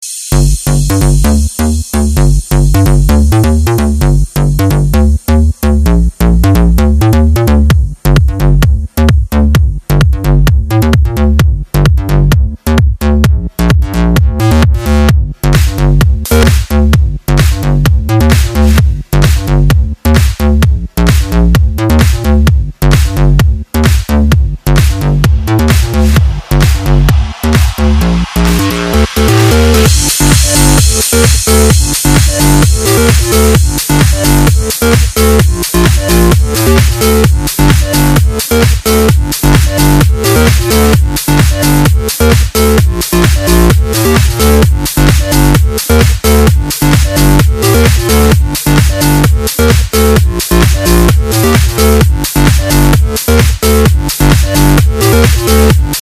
Категория: Танцевальные